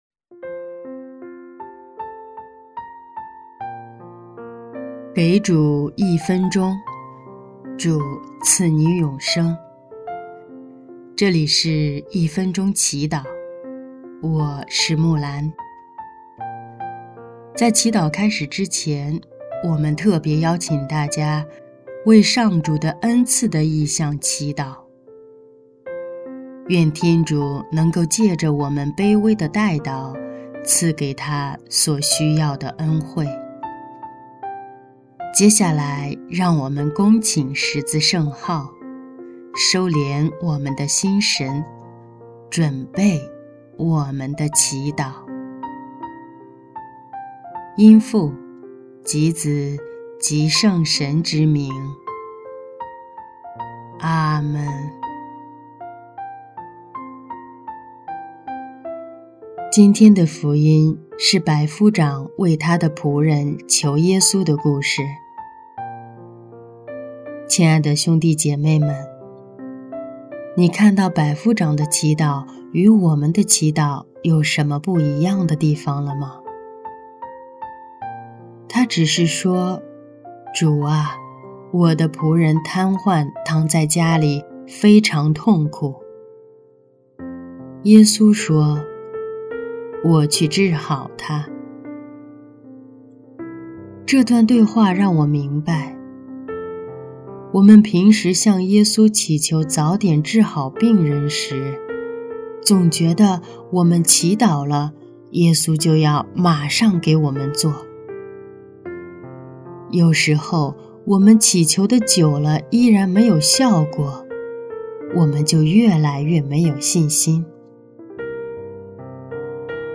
音乐：第一届华语圣歌大赛优秀奖《主我朝拜祢》